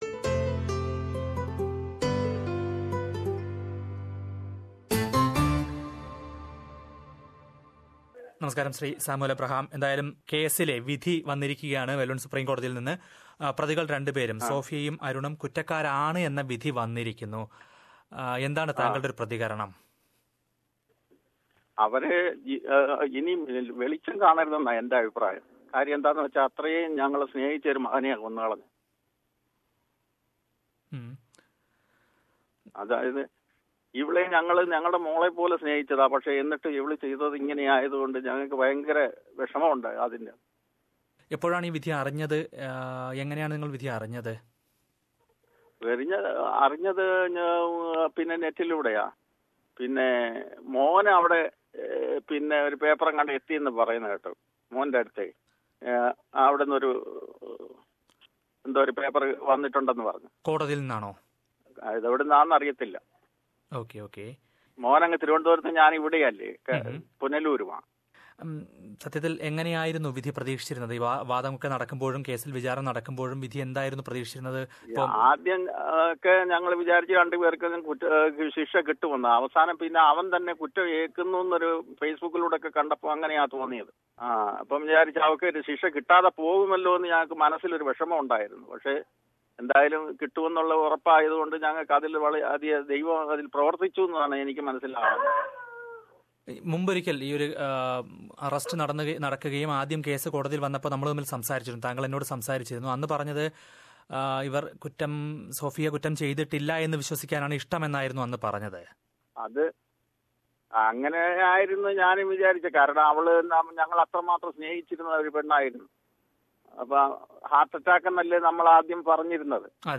in a telephone interview to SBS Malayalam from Kerala
Listen to the interview in Malayalam...